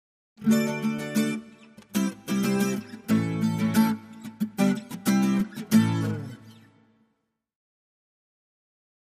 Acoustic Guitar - Happy Rhythm Type 1, Version C